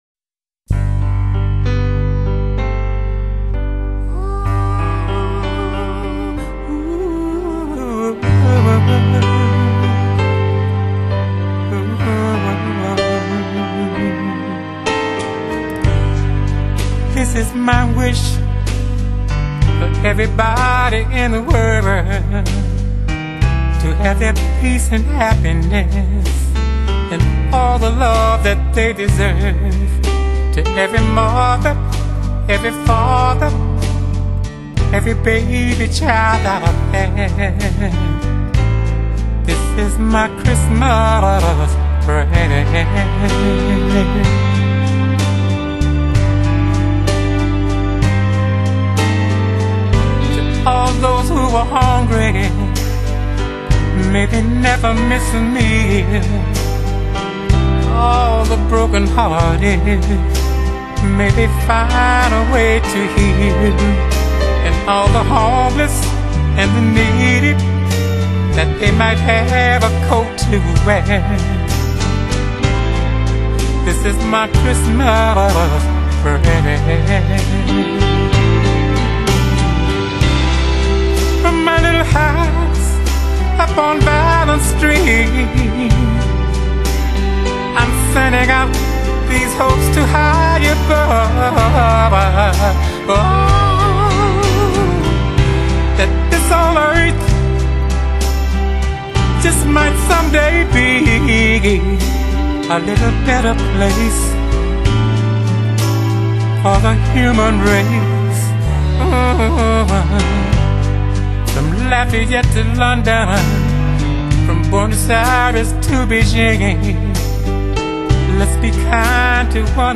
Genre: R&B, Soul, Pop